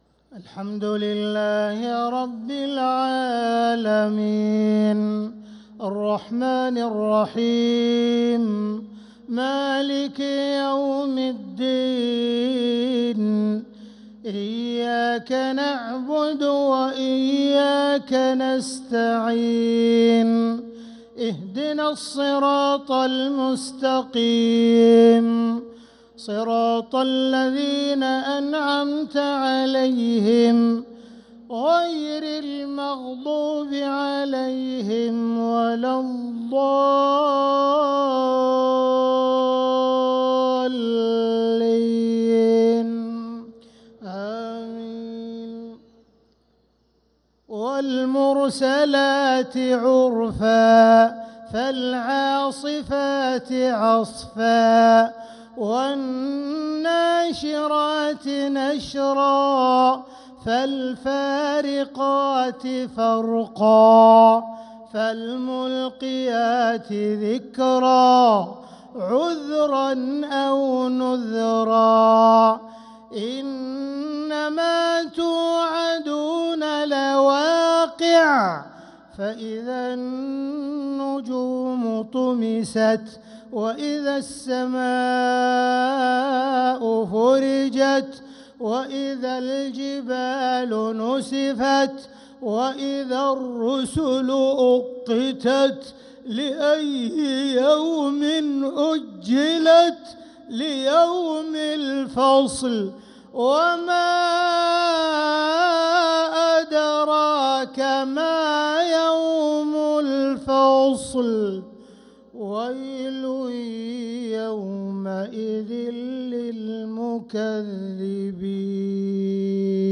مغرب الثلاثاء 4-9-1446هـ من سورة المرسلات 1-15 و 41-50 | Maghrib prayer from Surat al-Mursalat 4-3-2025 > 1446 🕋 > الفروض - تلاوات الحرمين